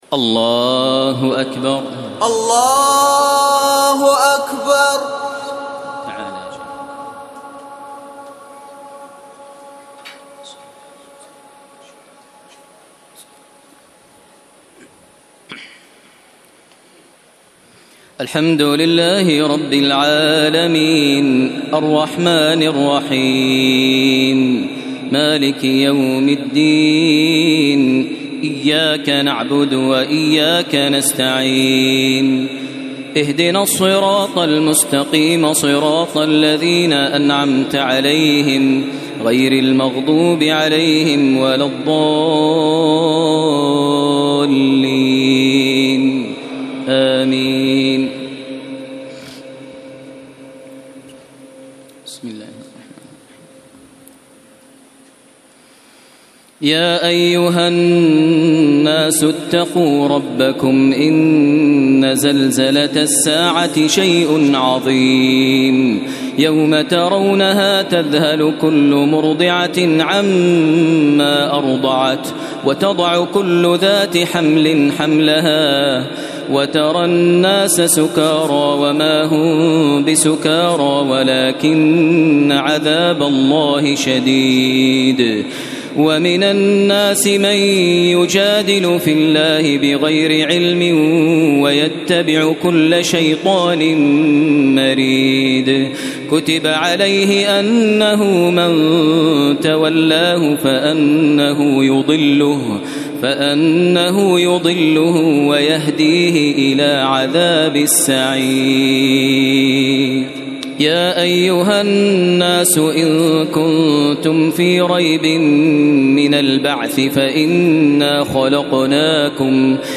تراويح الليلة السادسة عشر رمضان 1432هـ سورة الحج كاملة Taraweeh 16 st night Ramadan 1432H from Surah Al-Hajj > تراويح الحرم المكي عام 1432 🕋 > التراويح - تلاوات الحرمين